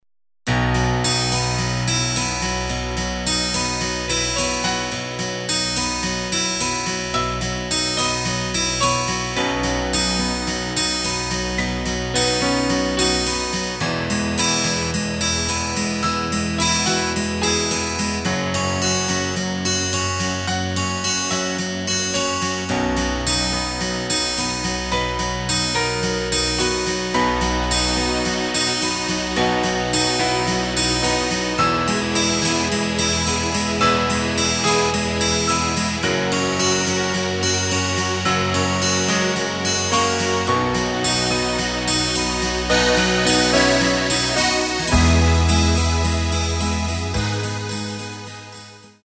Takt:          4/4
Tempo:         108.00
Tonart:            B
Ballade aus dem Jahr 2020!
Playback mp3 Demo